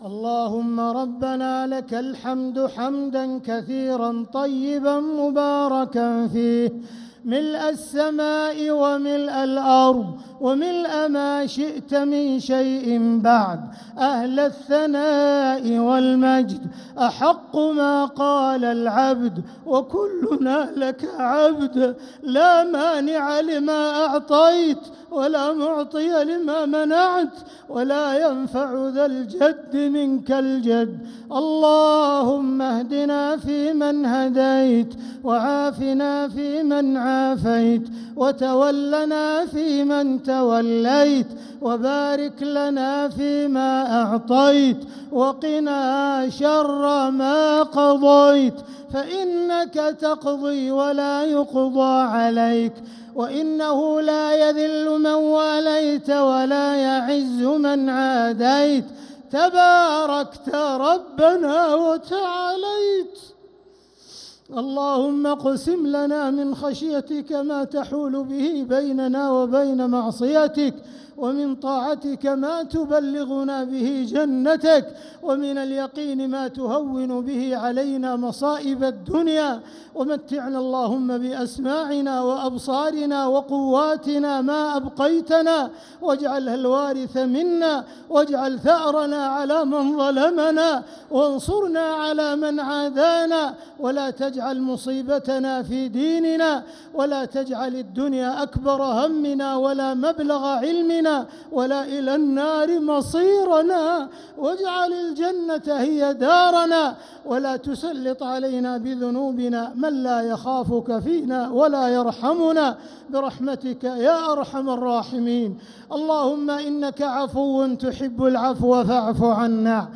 دعاء القنوت ليلة 4 رمضان 1446هـ | Dua 4th night Ramadan 1446H > تراويح الحرم المكي عام 1446 🕋 > التراويح - تلاوات الحرمين